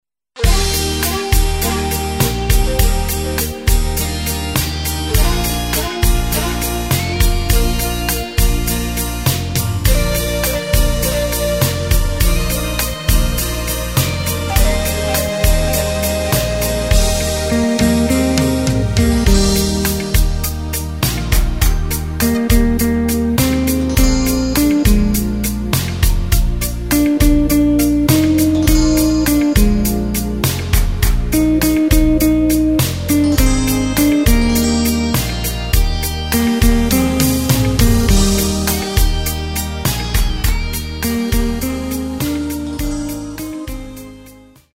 Takt:          4/4
Tempo:         102.00
Tonart:            Eb
Schlager aus dem Jahr 2017!